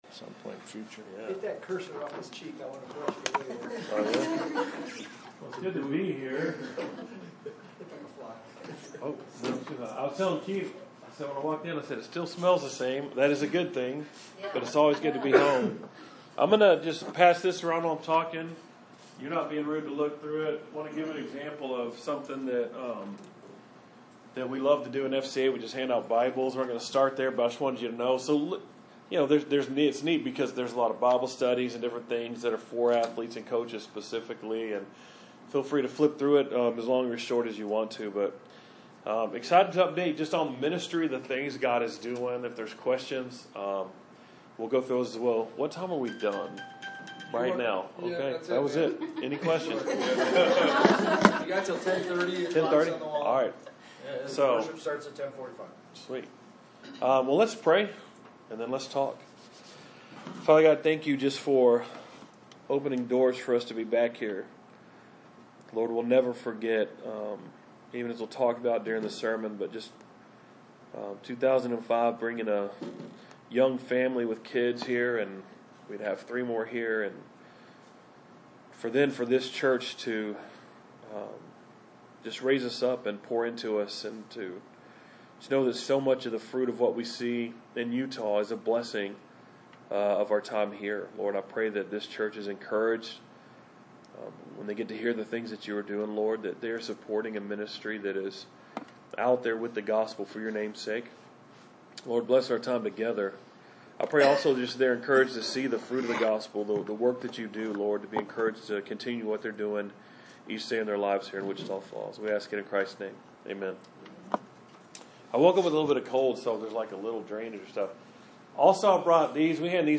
Service Type: Adult Bible Study